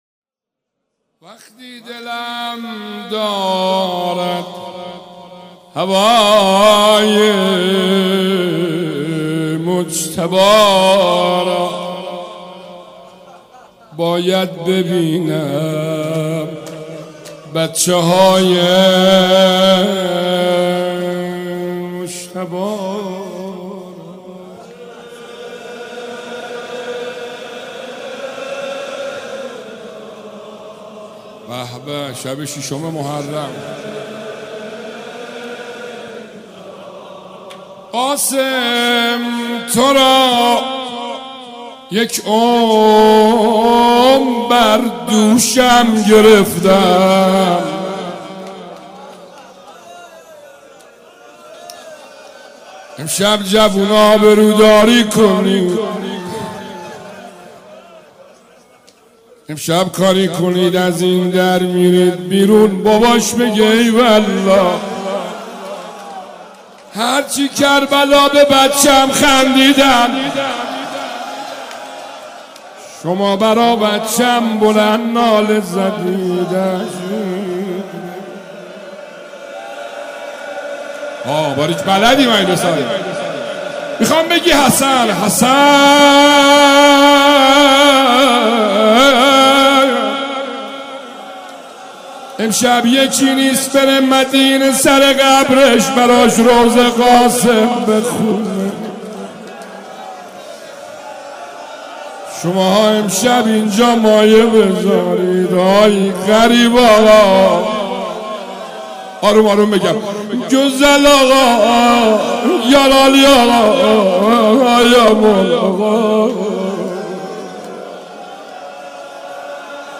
روضه
شب ششم محرم الحرام‌ جمعه ۱6 مهرماه ۱۳۹۵ هيئت ريحانة الحسين(س)
سبک اثــر روضه